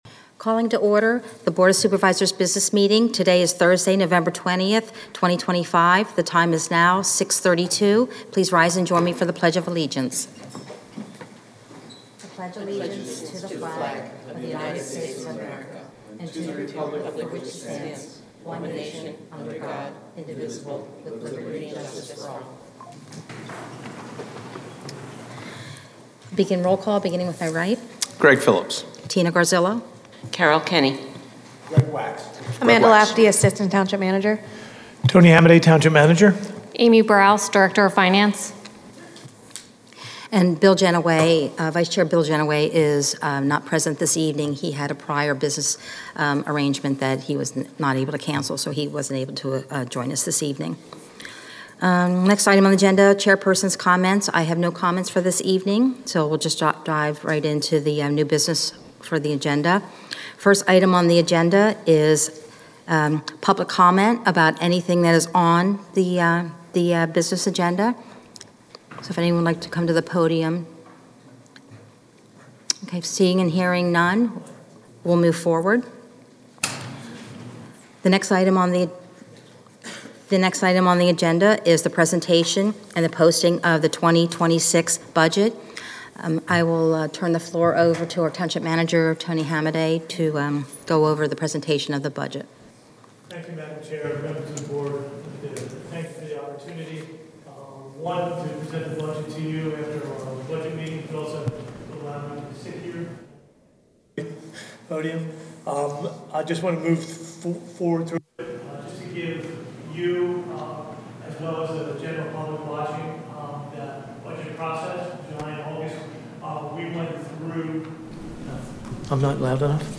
BOS Meeting - November 20, 2025